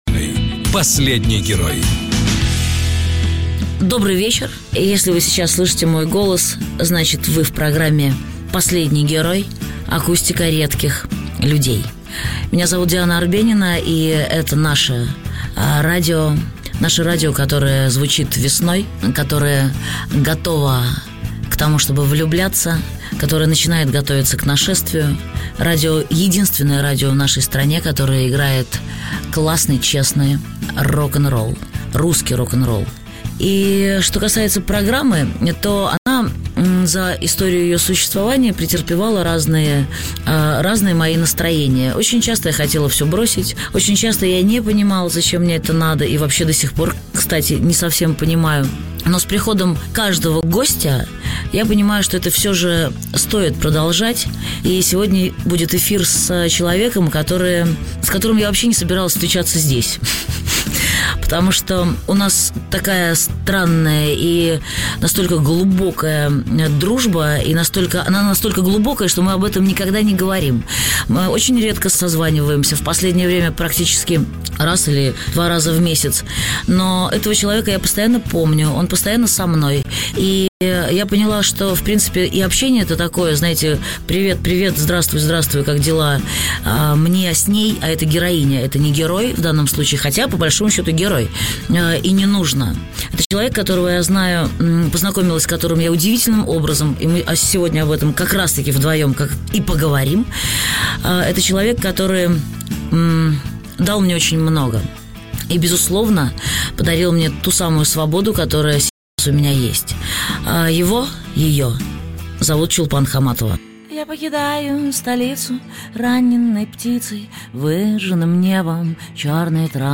«Последний герой»: интервью Чулпан Хаматовой